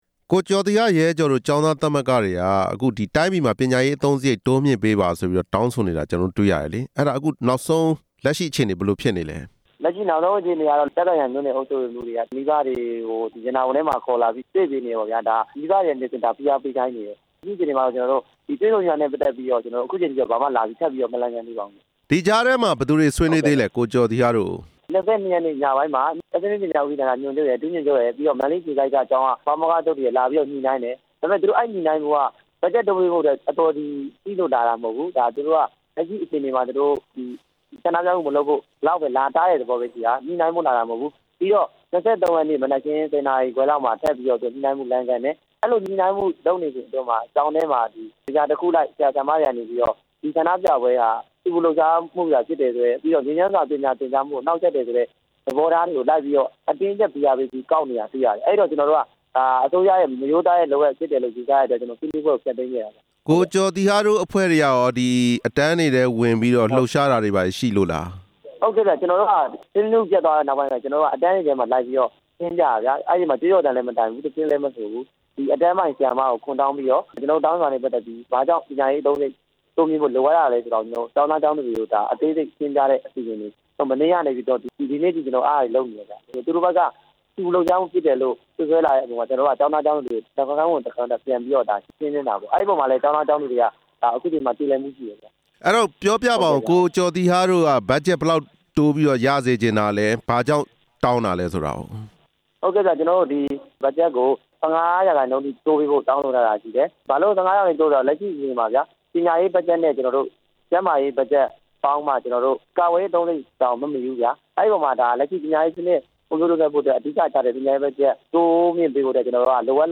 ပညာရေးဘတ်ဂျက် တိုးမြှင့်ရေး ဆန္ဒပြကျောင်းသားခေါင်းဆောင်နဲ့ မေးမြန်းချက်